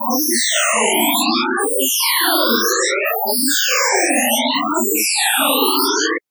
Coagula is a bitmap to sound converter.